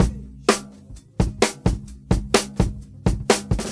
129 Bpm Drum Groove B Key.wav
Free drum loop sample - kick tuned to the B note. Loudest frequency: 1698Hz
.WAV .MP3 .OGG 0:00 / 0:04 Type Wav Duration 0:04 Size 319,8 KB Samplerate 44100 Hz Bitdepth 16 Channels Mono Free drum loop sample - kick tuned to the B note.
129-bpm-drum-groove-b-key-oIR.ogg